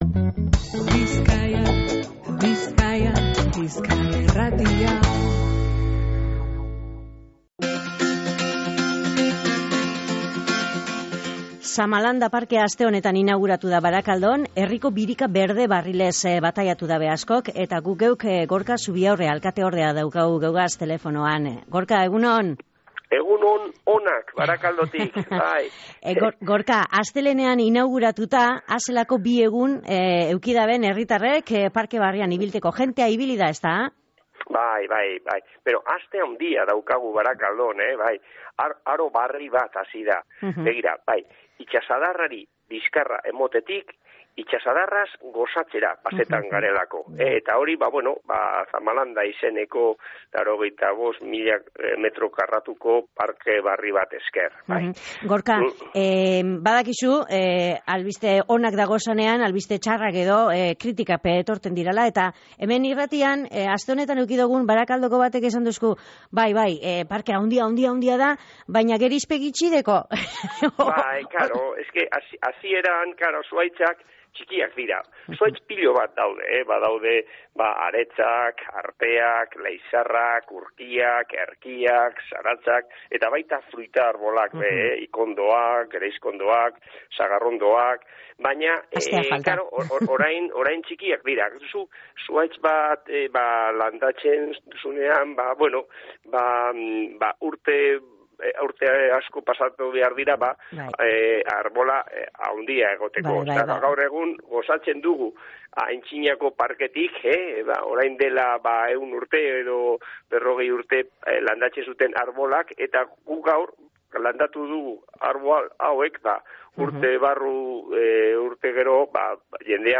Gorka Zubiaurre Barakaldoko alkateordearekin izan gara parkearen xehetasunak jakiteko. Proiektuaren lehen fasea inauguratu dabela kontau deusku.